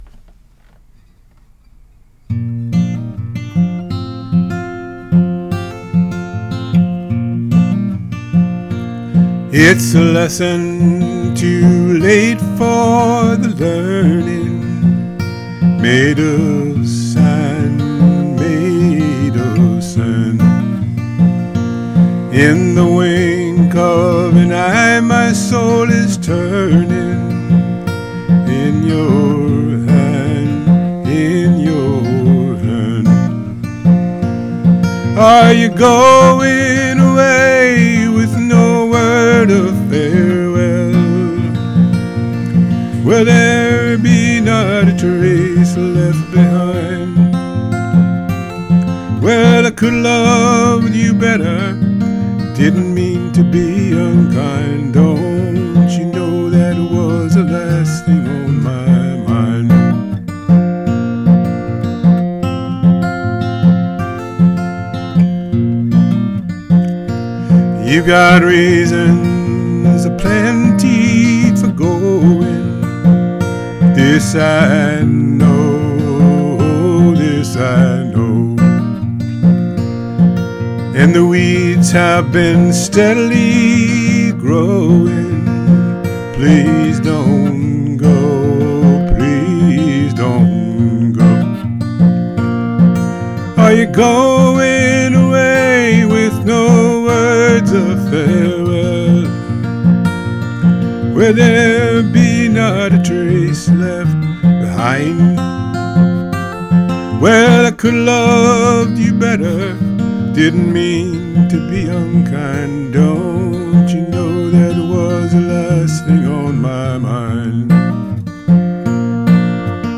This lost love ballad